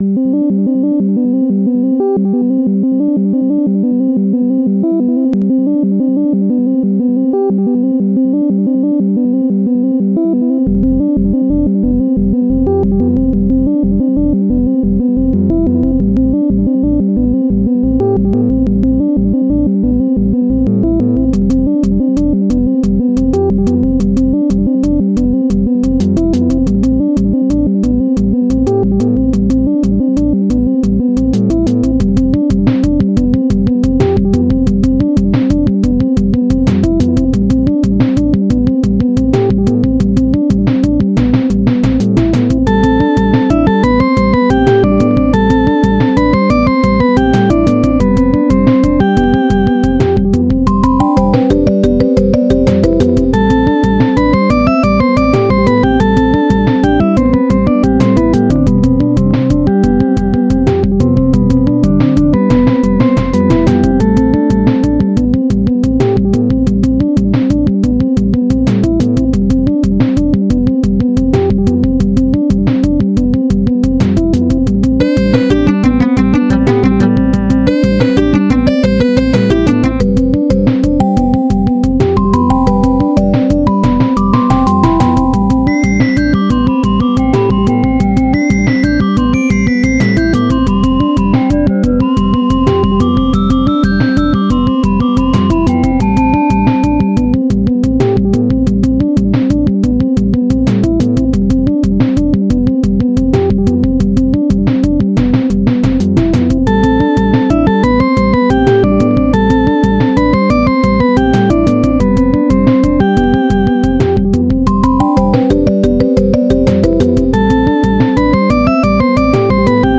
SPC700 chiptune
snes 16 bits furnace tracker